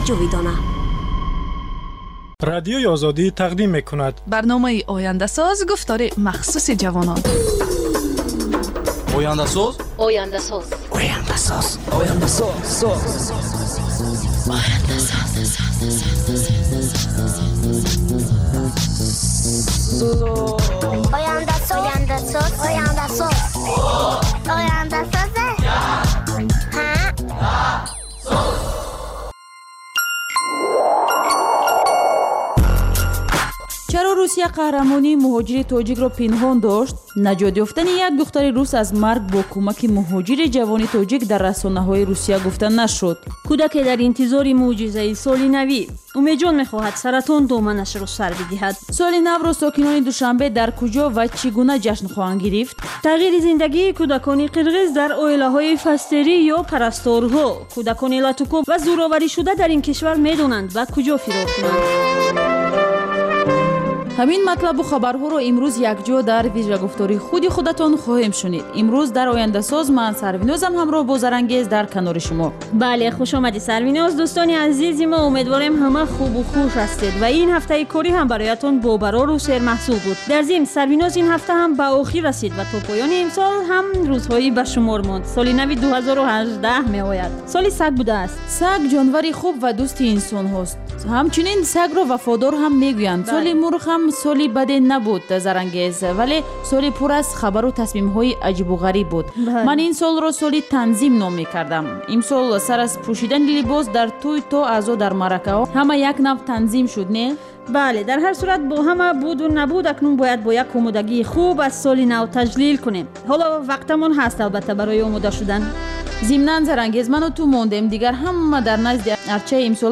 "Ояндасоз" барномаи вижаи ҷавонон, ки муҳимтарин масоили сиёсӣ, иқтисодӣ, иҷтимоӣ ва фарҳангии Тоҷикистону ҷаҳонро аз дидгоҳи худи онҳо ва коршиносон таҳлил ва баррасӣ мекунад. Бар илова, дар ин гуфтор таронаҳои ҷаззоб ва мусоҳибаҳои ҳунармандон тақдим мешавад.